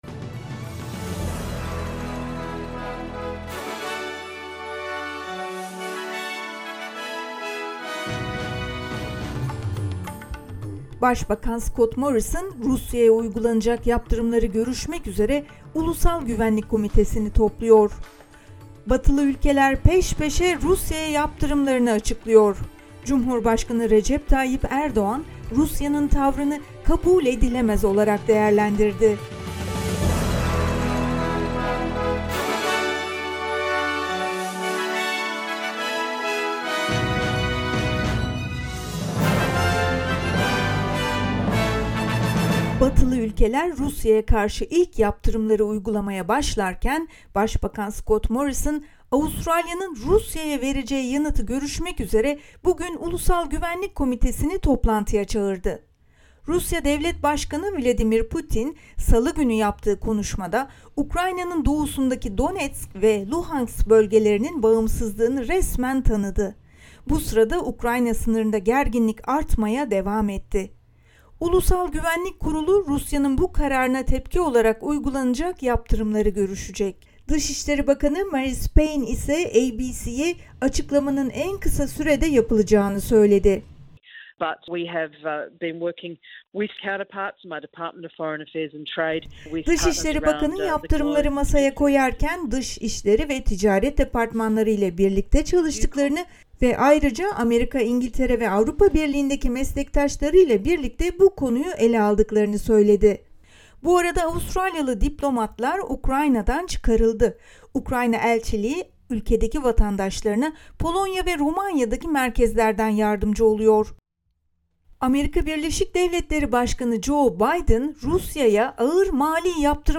SBS Türkçe Haber Bülteni 23 Şubat